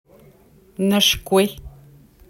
Il y a eu une confusion au départ sur l’orthographe du nom, notamment en raison d’une complexité phonétique entre les voyelles a et i. Selon le contexte, celles-ci peuvent avoir une prononciation similaire.
Prononciation Nashkue